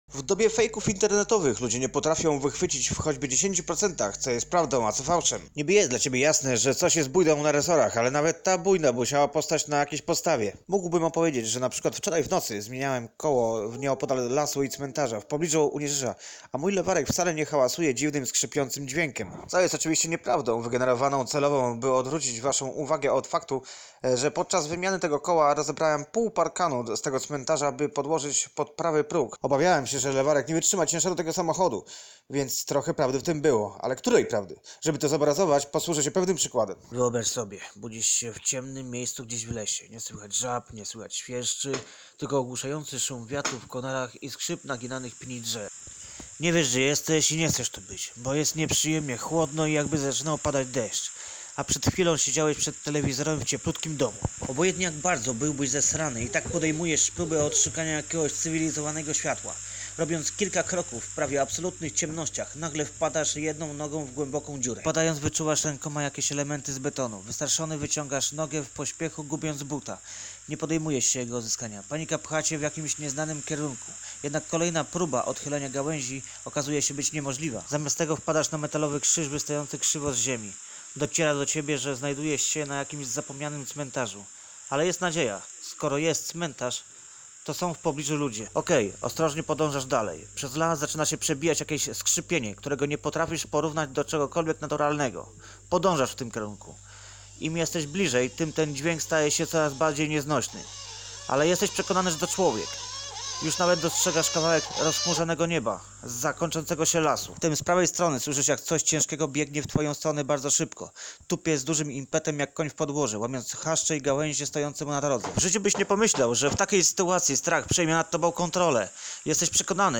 Psychodeliczne opowieści z zaczarowanego lasu